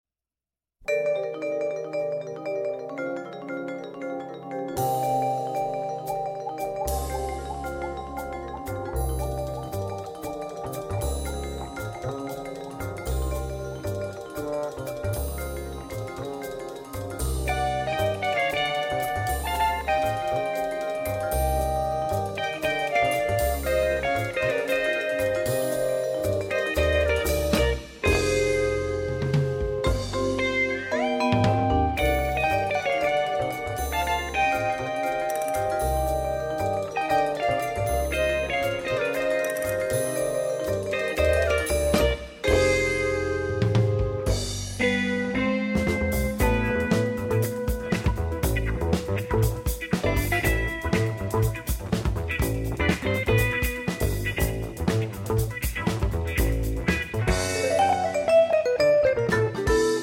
vibraphone
drums and percussion
guitar
bass